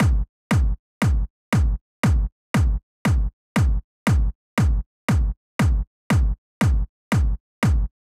28 Kick.wav